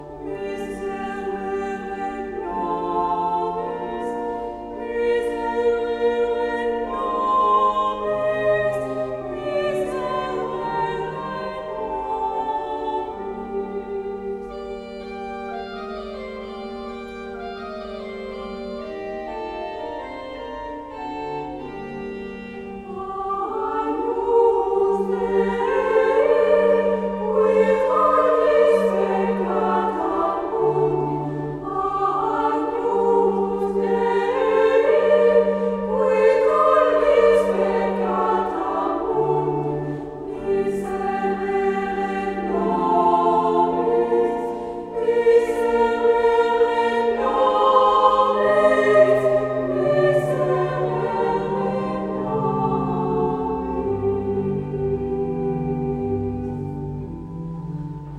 2 et 3 voix égales + orgue
Audios : version d'origine pour voix égales et orgue